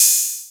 puredata/resources/808_drum_kit/hihats/808-OpenHiHats14.wav at master
808-OpenHiHats14.wav